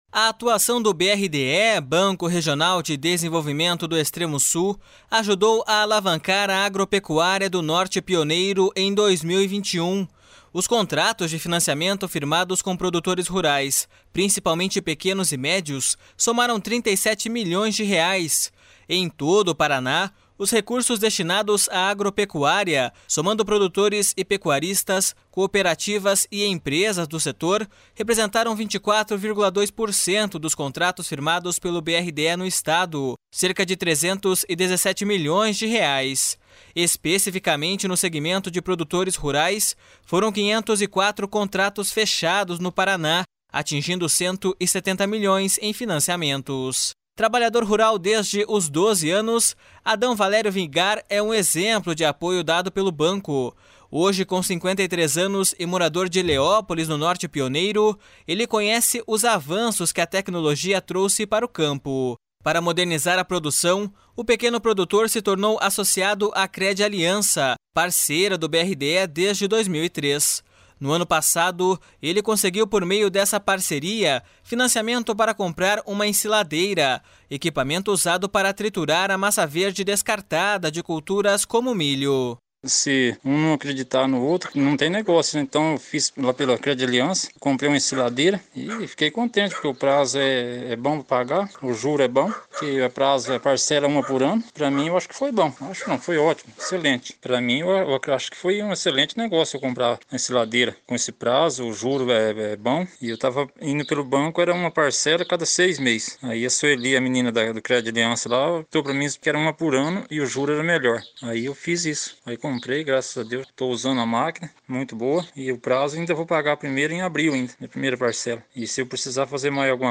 O presidente do BRDE, Wilson Bley, destaca a importância dos financiamentos e o papel do banco no impulsionamento do setor.// SONORA WILSON BLEY.//